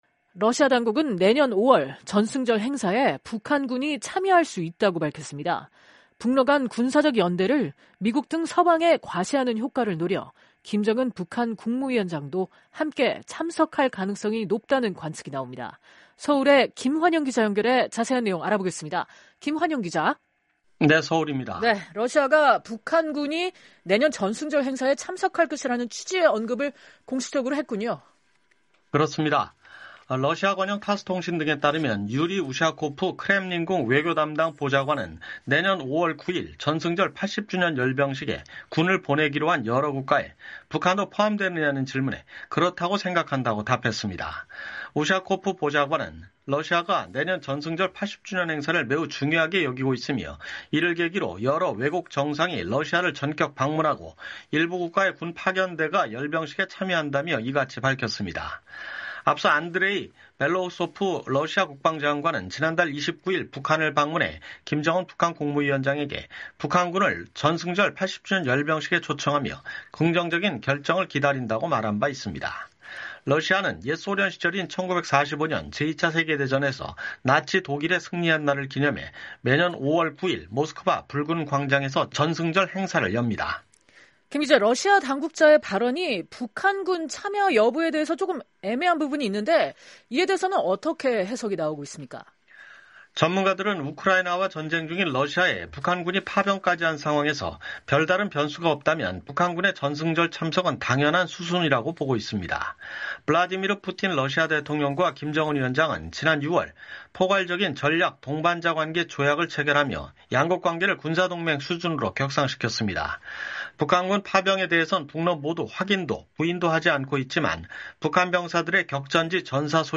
러시아 당국은 내년 5월 전승절 행사에 북한 군이 참여할 수 있다고 밝혔습니다. 북러 간 군사적 연대를 미국 등 서방에 과시하는 효과를 노려 김정은 북한 국무위원장도 함께 참석할 가능성이 높다는 관측이 나옵니다.